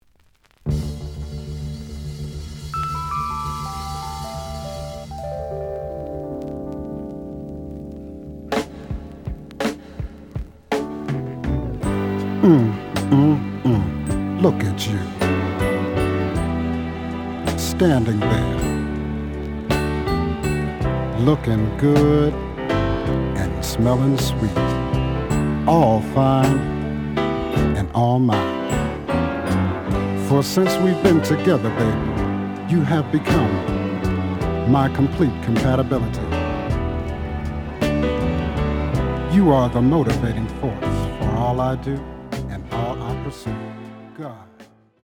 The audio sample is recorded from the actual item.
●Genre: Soul, 70's Soul
Slight damgae on B side edge.